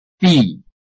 拼音查詢：【饒平腔】di ~請點選不同聲調拼音聽聽看!(例字漢字部分屬參考性質)